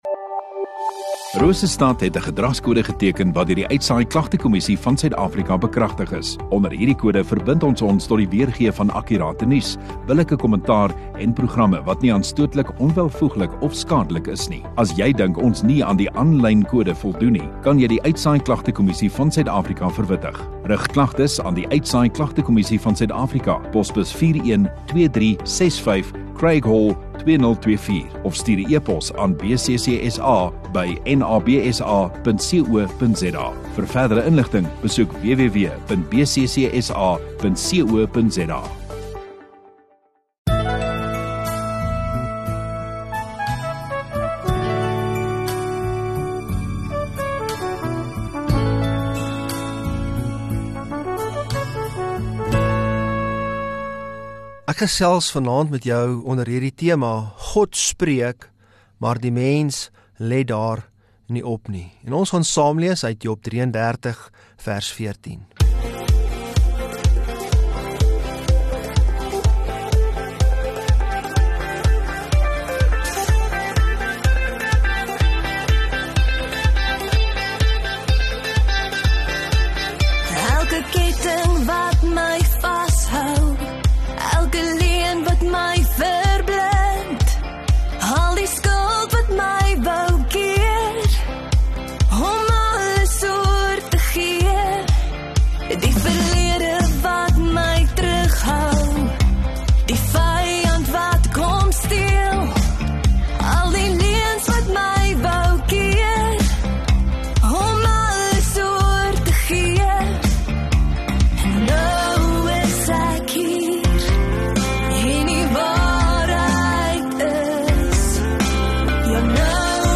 19 Jan Sondagaand Erediens